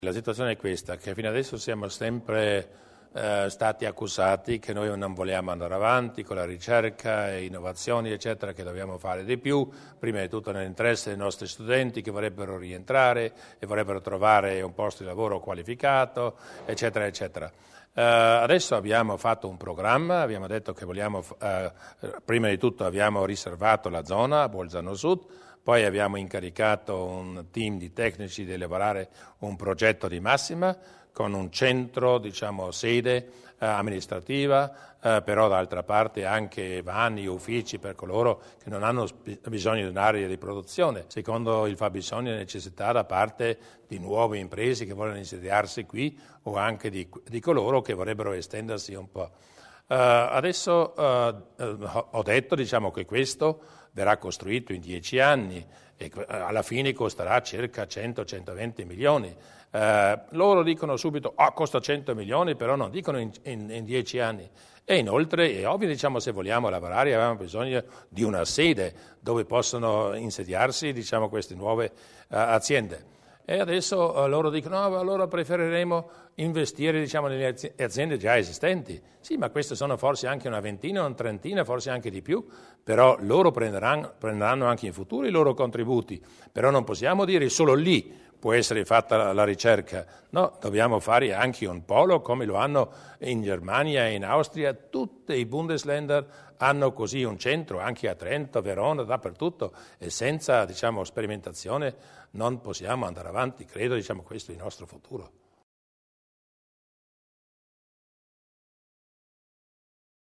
Di seguito alcune delle decisioni assunte dalla Giunta provinciale nella seduta di oggi (19 luglio) e illustrate dal presidente Luis Durnwalder nella successiva conferenza stampa.